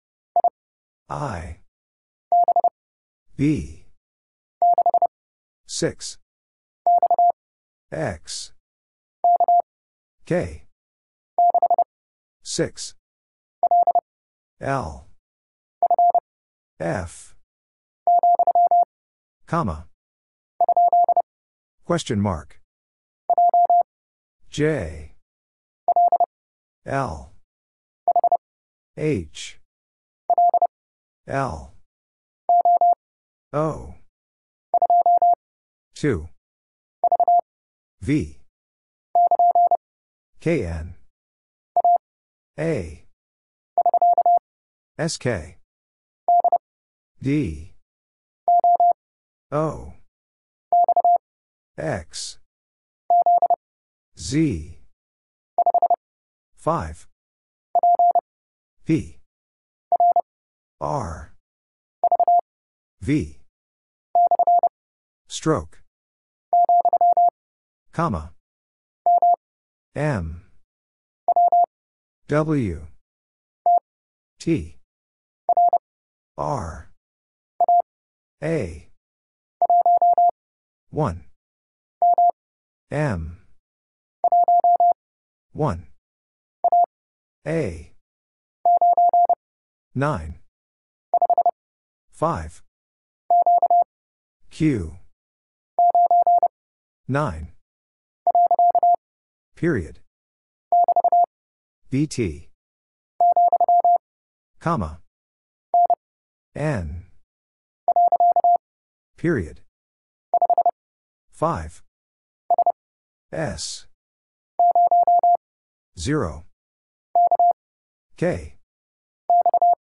Morse Code Ninja Practice at 30wpm
Single Character (Letter:Number:Punctuation:Prosign) - Mind-Melt - 30wpm.m4a